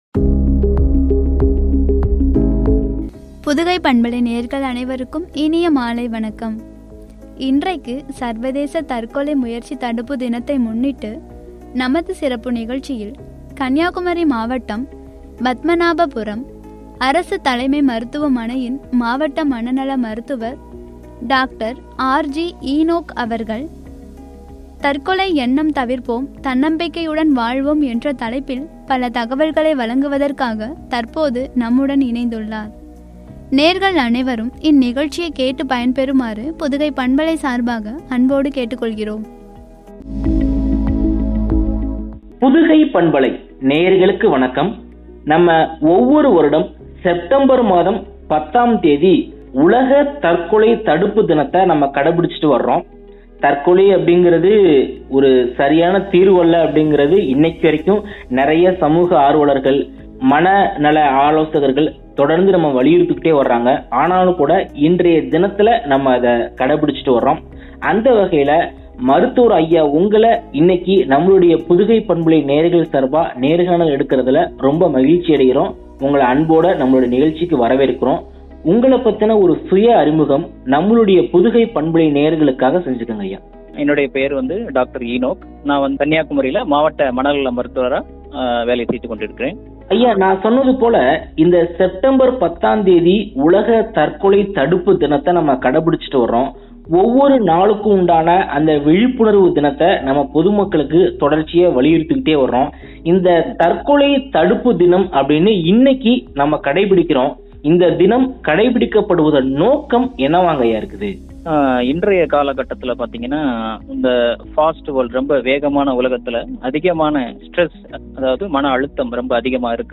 என்னும் தலைப்பில் வழங்கிய உரையாடல்.